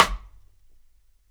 RIM CLICK.wav